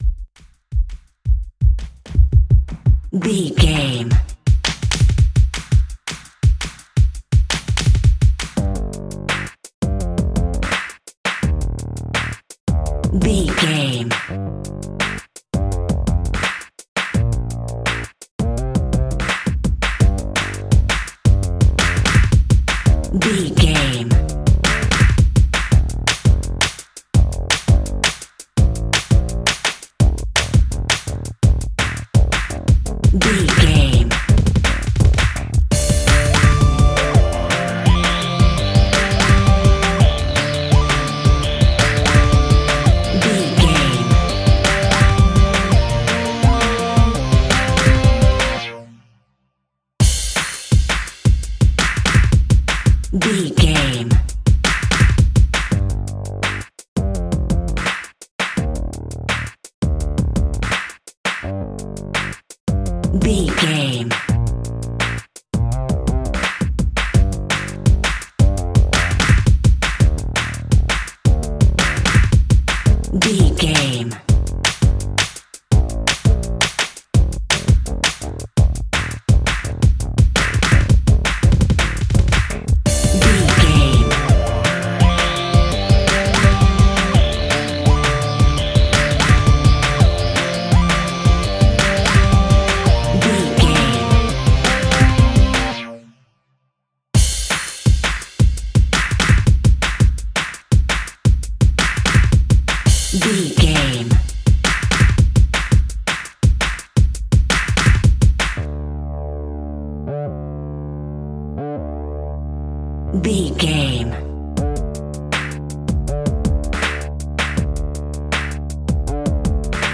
Fast paced
Aeolian/Minor
Fast
aggressive
dark
driving
energetic
drum machine
synthesiser
Drum and bass
break beat
electronic
sub bass
industrial